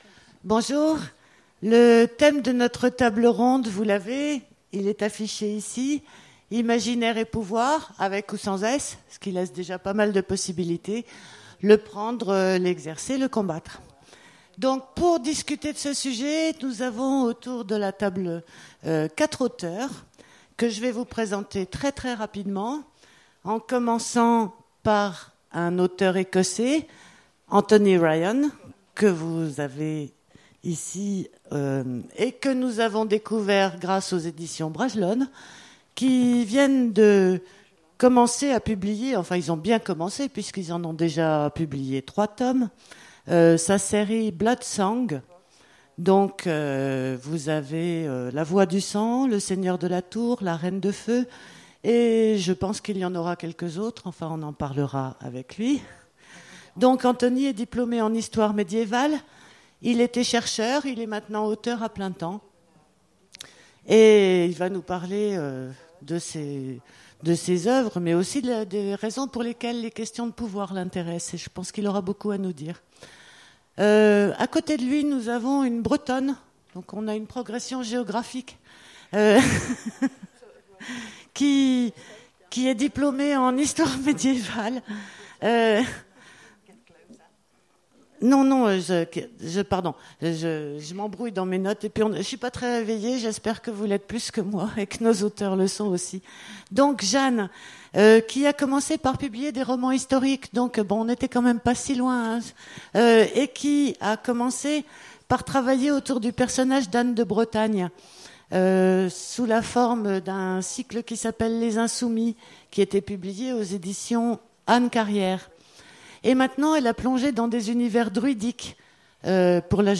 Imaginales 2016 : Conférence Imaginaire et pouvoir(s)